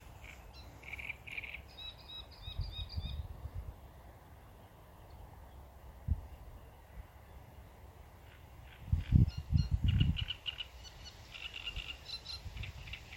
Birds -> Warblers ->
Great Reed Warbler, Acrocephalus arundinaceus
StatusSinging male in breeding season